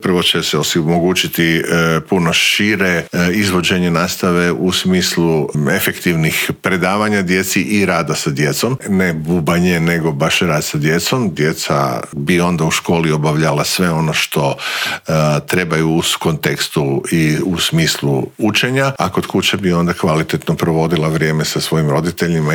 ZAGREB - U ponedjeljak će zvono označiti početak nove školske godine, a prije nego što se školarci vrate pred ploču, pred mikrofon Media servisa u Intervjuu tjedna stao je ministar znanosti i obrazovanja Radovan Fuchs.